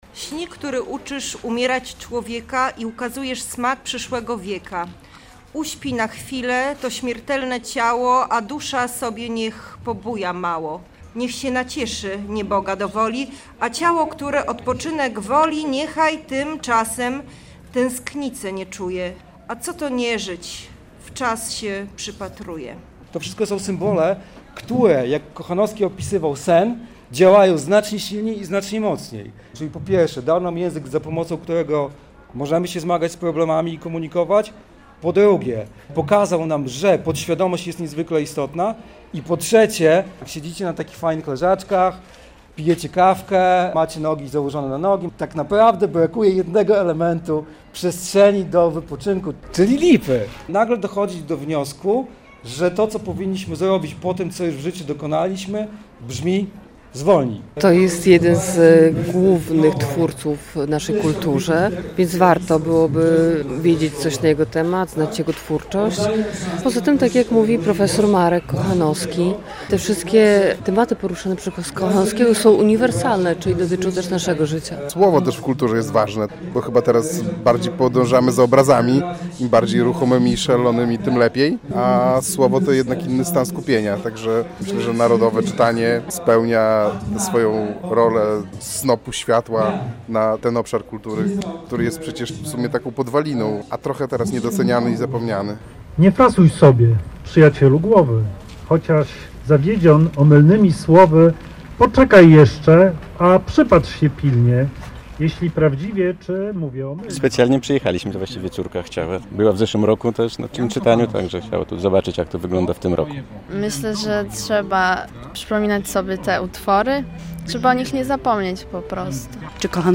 Narodowe Czytanie w Białymstoku - relacja